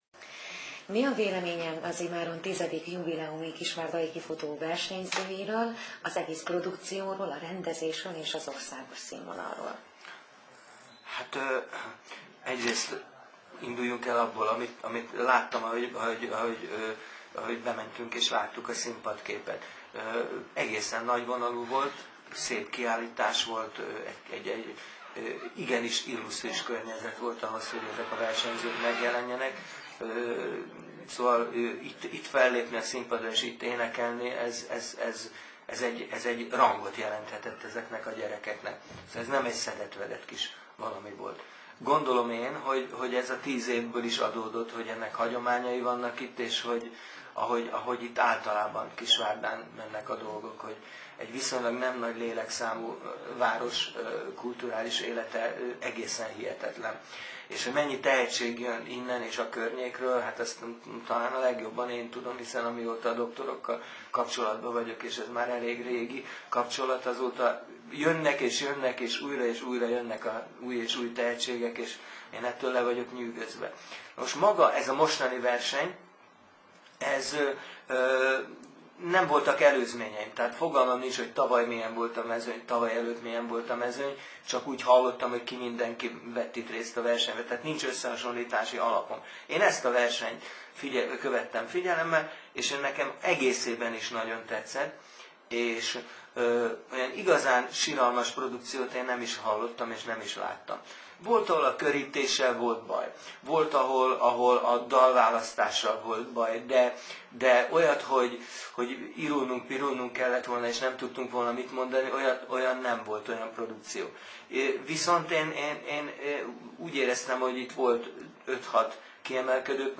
A Kaméleon Kisvárdán vett részt a Kifutó - 2008-as énekversenyen. A zsűrivel készített rádióriportot itt hallgathatják meg.